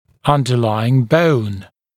[ˌʌndə’laɪŋ bəun][ˌандэ’лаин боун]подлежащая кость